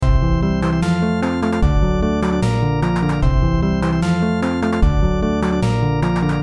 Music With Clapping And Rhythm - Bouton d'effet sonore